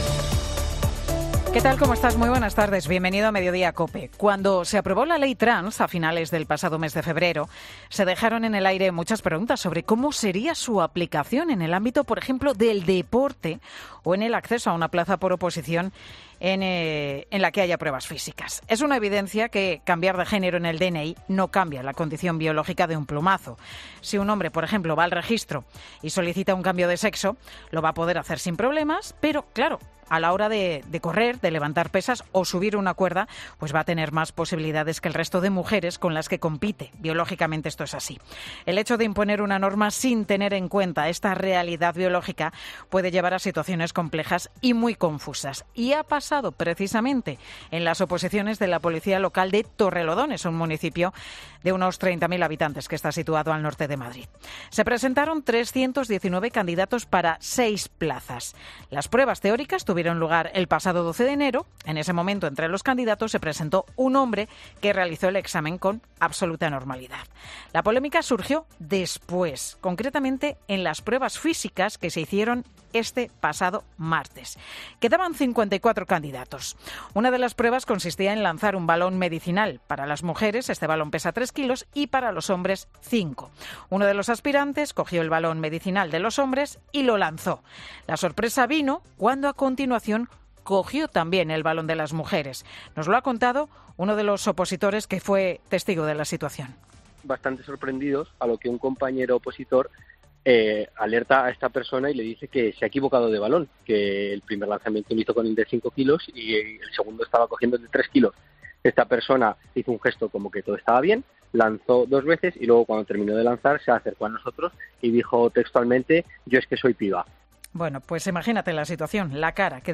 Monólogo